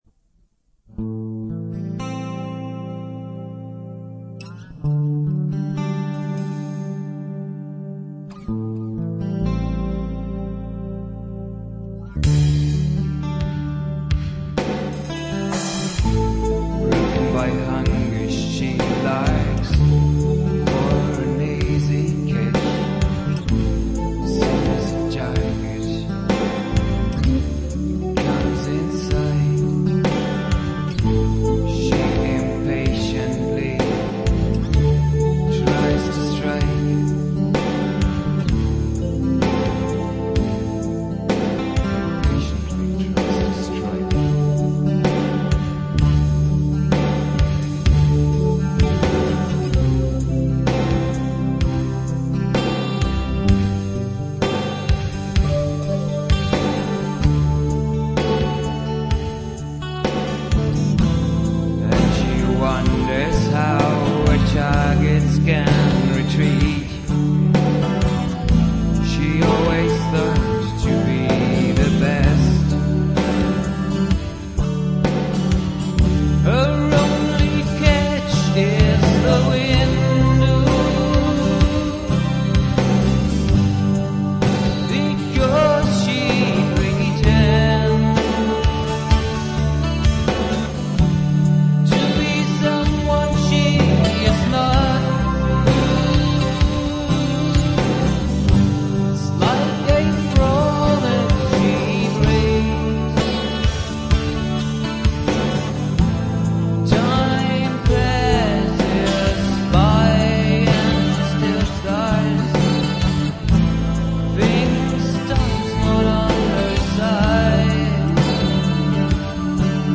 TypEP (Studio Recording)